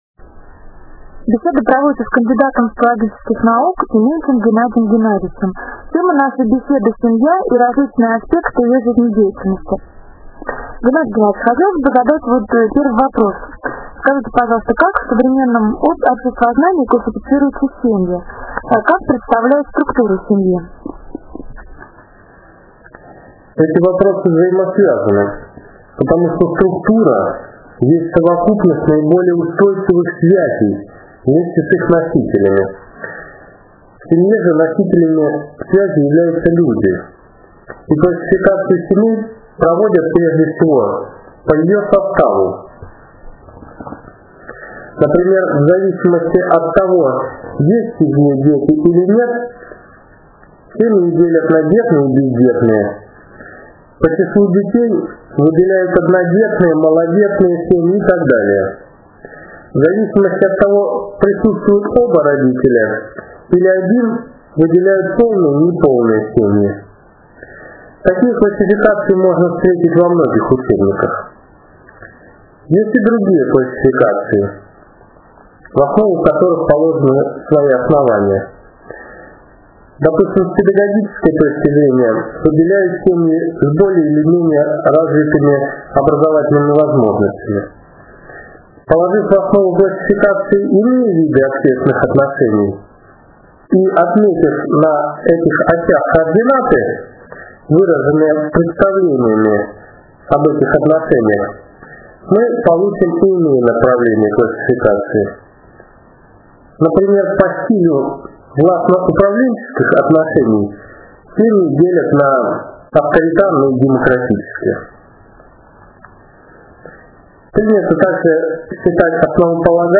Интервью по теме "Семья"